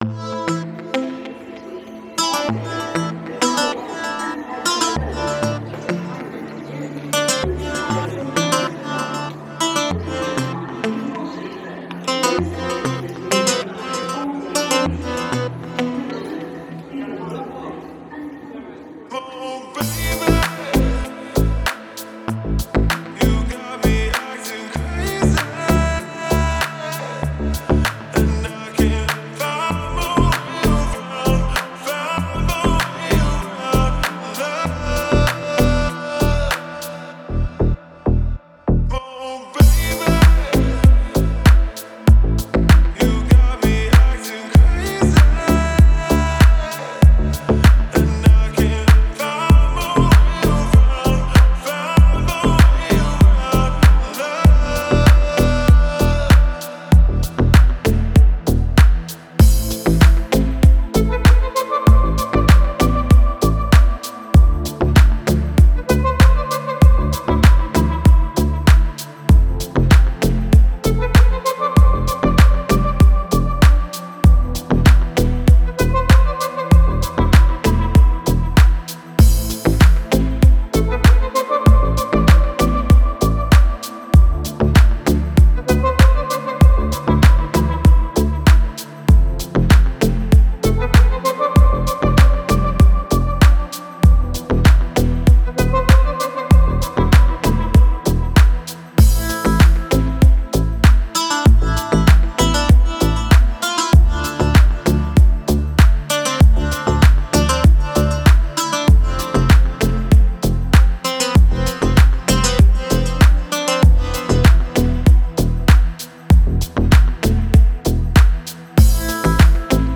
Категория: Deep House музыка
Дип Хаус музыка